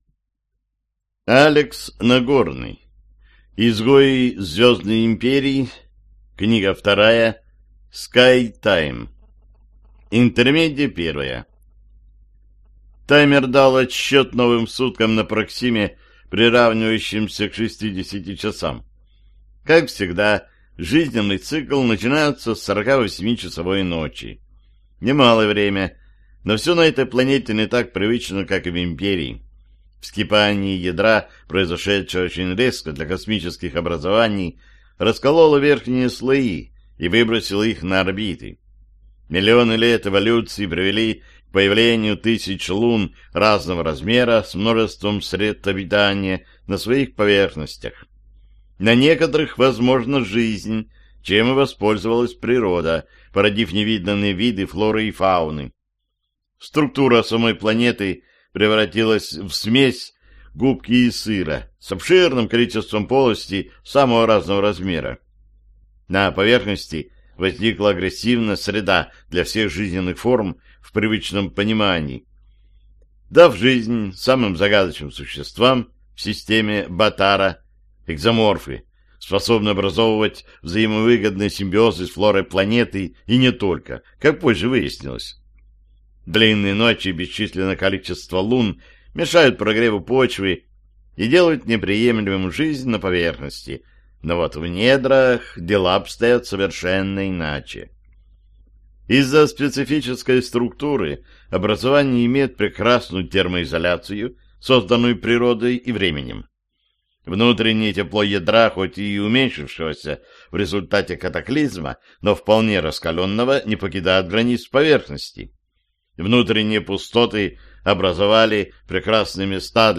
Аудиокнига Скайвэй. Книга 2. Скайтайм | Библиотека аудиокниг